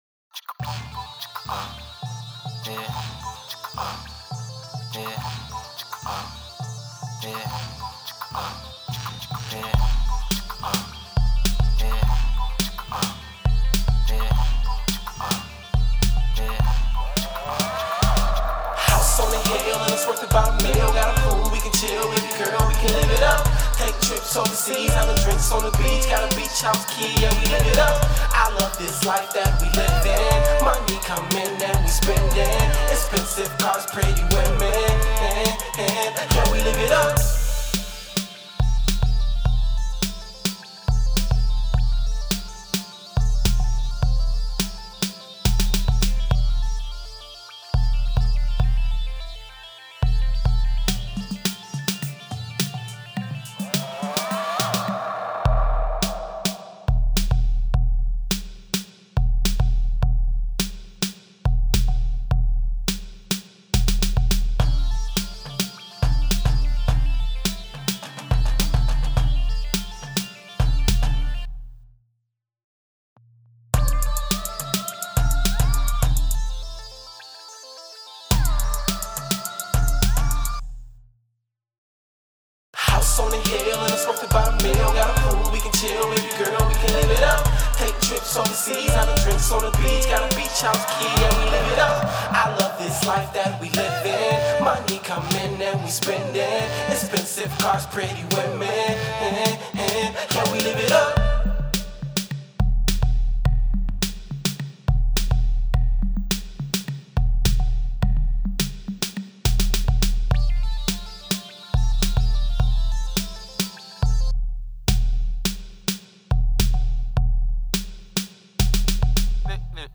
Heart Monitor_Flat Line